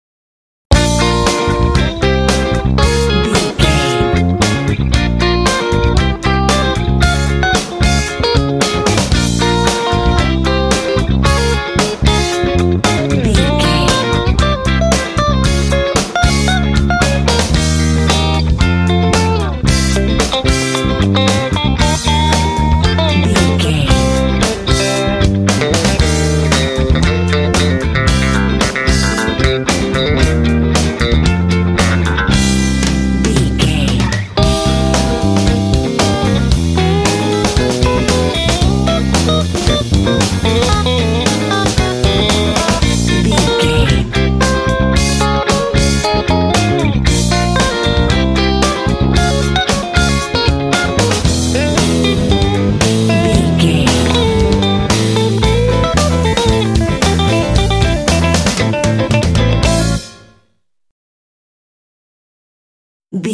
Uplifting
Aeolian/Minor
happy
bouncy
groovy
electric guitar
bass guitar
drums
blues